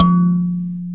SANZA 2 F2.WAV